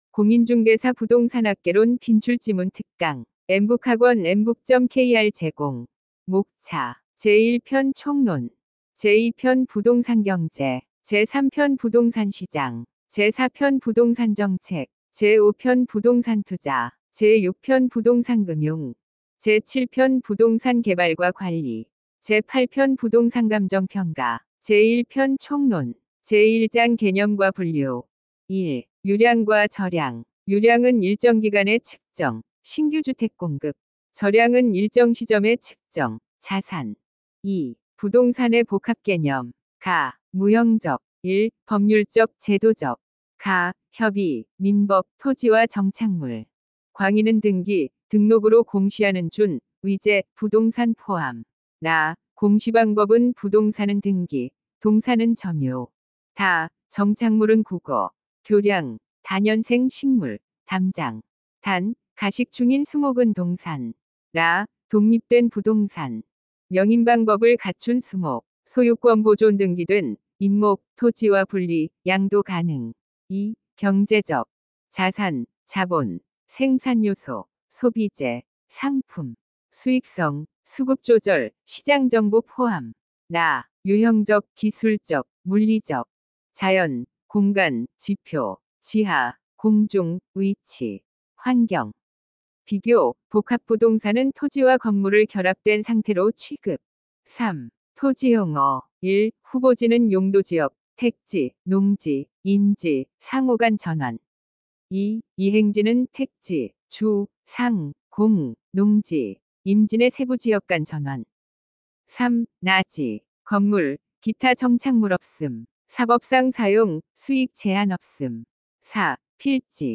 엠북학원은 우리나라 최초의 인공지능이 강의하는 사이버학원이며, 2025년 4월 28일 개원하였습니다.
부동산학개론-빈출지문-특강-샘플.mp3